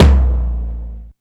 Tom1.aif